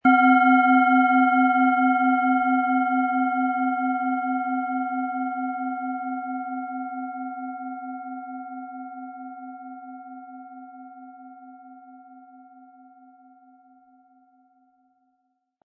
Planetenton 1
Es ist eine Planetenklangschale Biorhythmus Geist aus einem kleinen Meisterbetrieb in Asien.
Im Sound-Player - Jetzt reinhören können Sie den Original-Ton genau dieser Schale anhören.
Lassen Sie die Klangschale mit dem kostenlosen Klöppel sanft erklingen und erfreuen Sie sich an der wohltuenden Wirkung Ihrer Biorhythmus Geist.
MaterialBronze